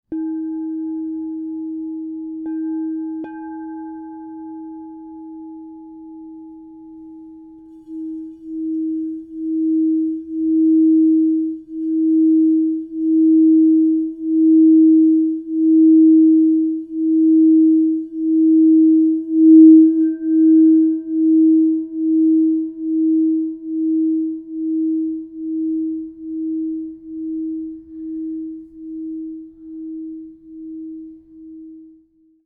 Divine Father 8″ D# +40 Crystal Tones singing bowl
Experience the majestic energy of the Crystal Tones® Divine Father 8 inch D# Positive 40 Singing Bowl, resonating at D# +40 to inspire strength, wisdom, and protection.
The 8-inch size delivers focused and rich frequencies, making it ideal for both personal and group sound healing practices.
528Hz (+)
D#